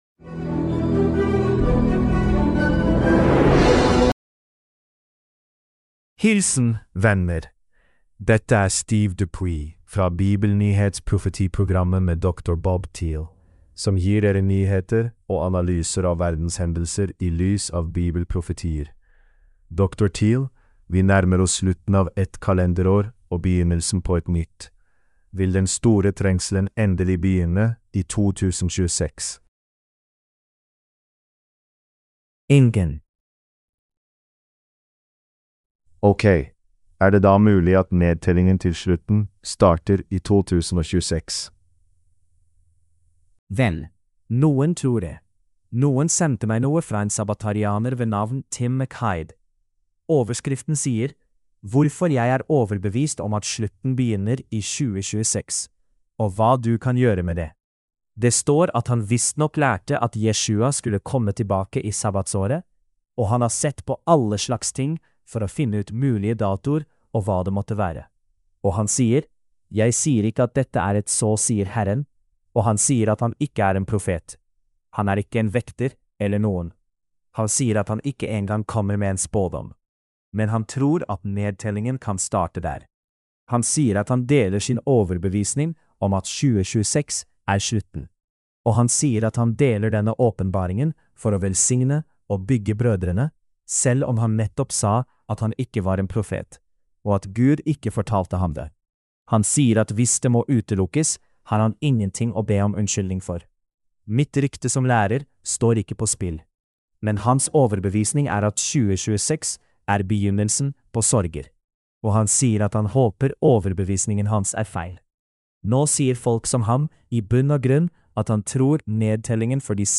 Norwegian Sermonette – Bible Prophecy News